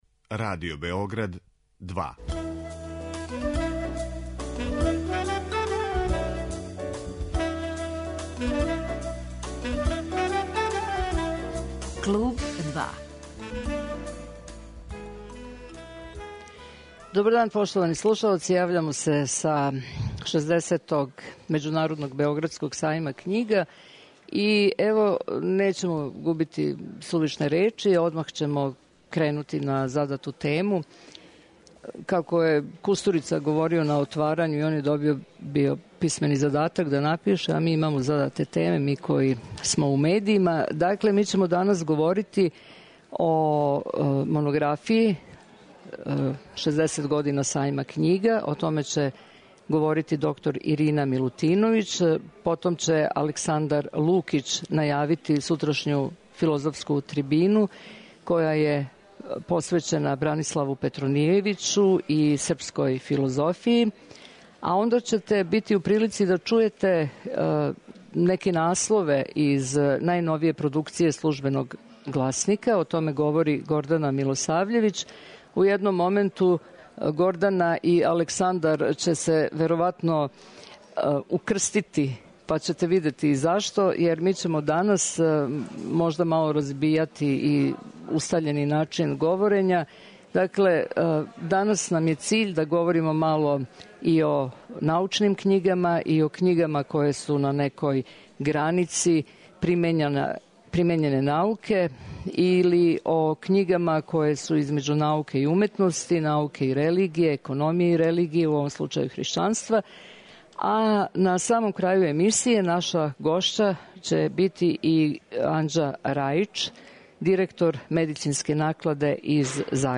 Директно са Сајма књига